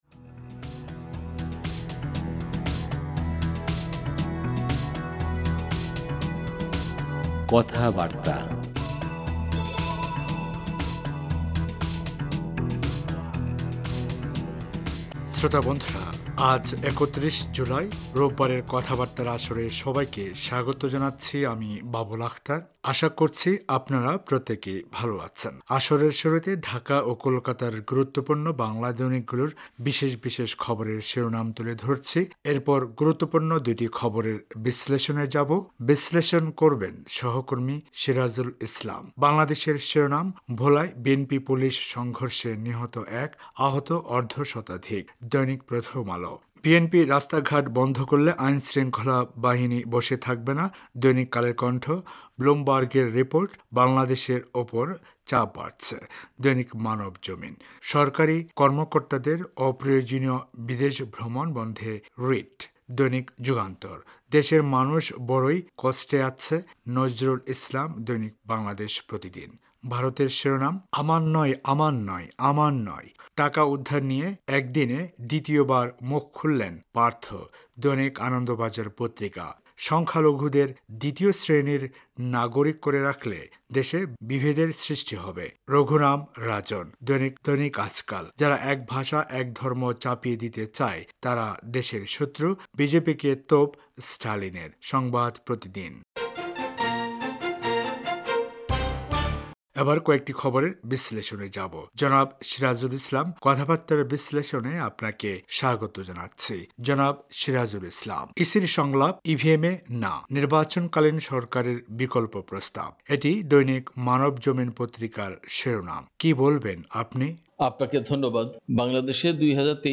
আসরের শুরুতে ঢাকা ও কোলকাতার গুরুত্বপূর্ণ বাংলা দৈনিকগুলোর বিশেষ বিশেষ খবরের শিরোনাম তুলে ধরছি। এরপর গুরুত্বপূর্ণ দুটি খবরের বিশ্লেষণে যাবো।